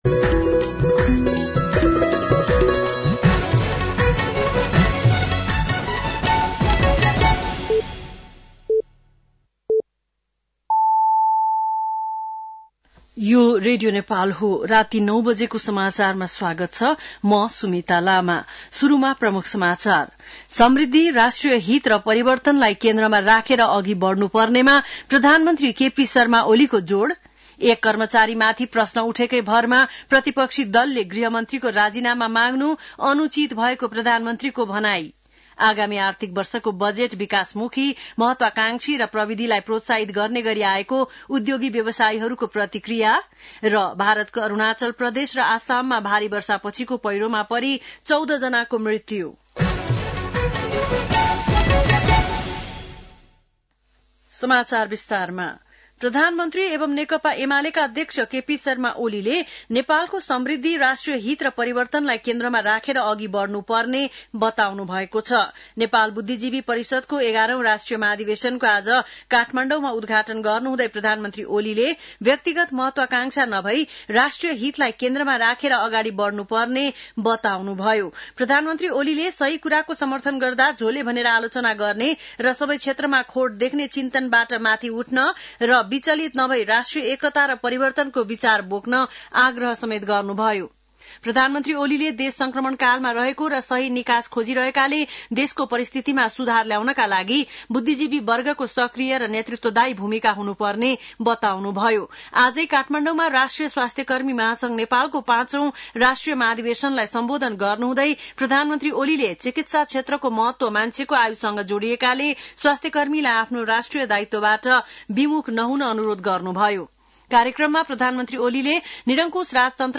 बेलुकी ९ बजेको नेपाली समाचार : १७ जेठ , २०८२
9-PM-Nepali-NEWS-.mp3